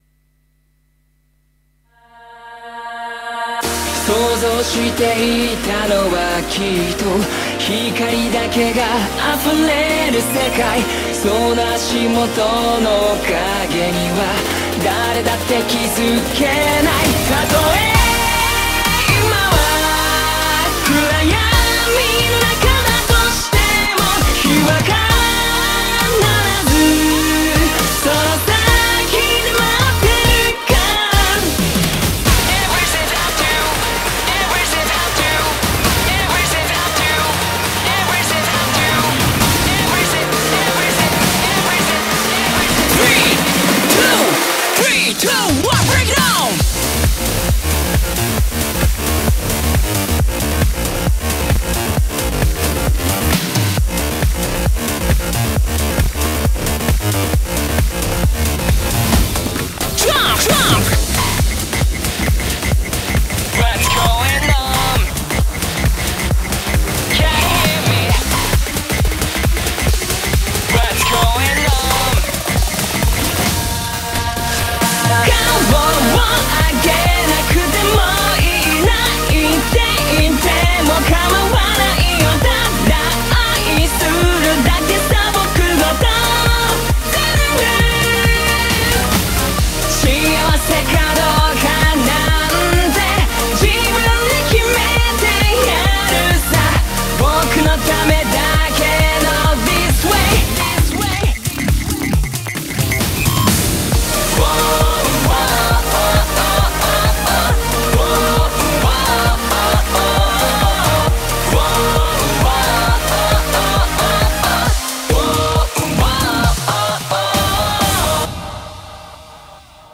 BPM134
Audio QualityPerfect (Low Quality)